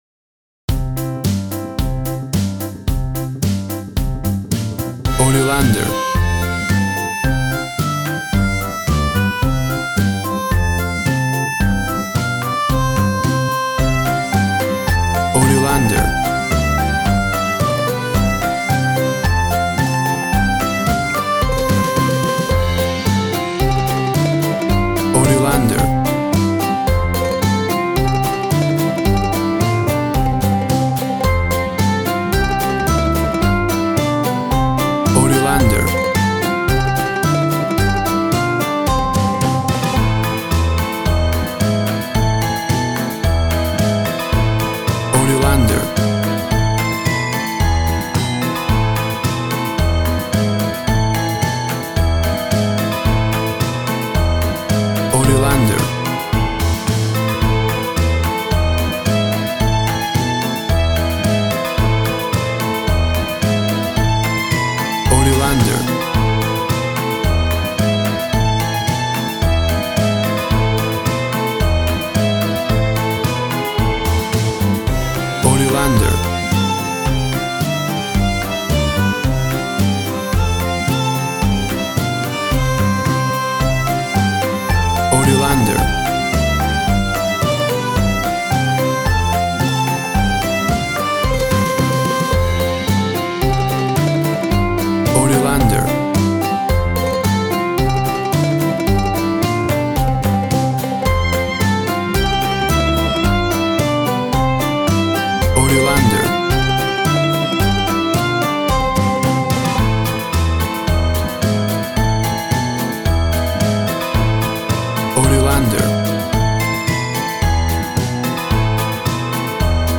Upbeat, uptempo and exciting!
Tempo (BPM) 110